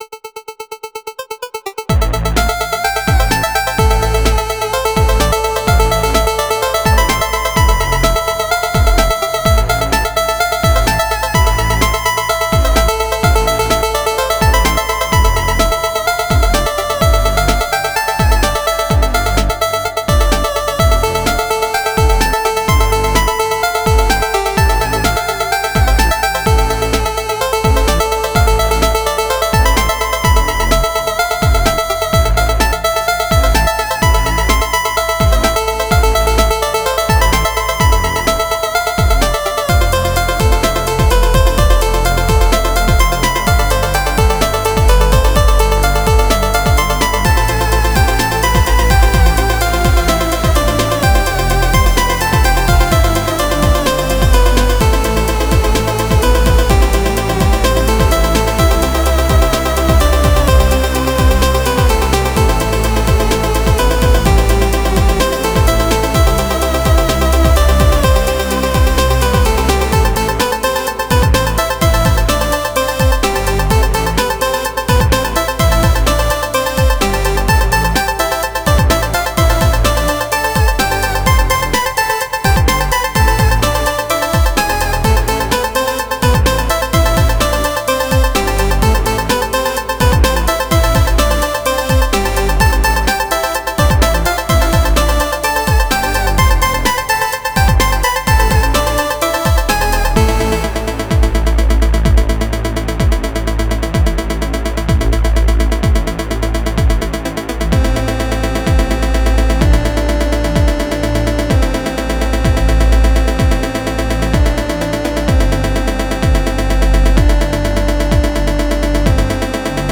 Please enjoy this chiptune adventure for heroes battling for freedom and goodness.